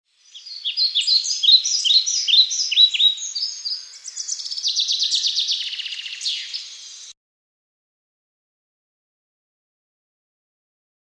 Ambient sound effects
Descargar EFECTO DE SONIDO DE AMBIENTE PAJARILLOS PAJARITOS - Tono móvil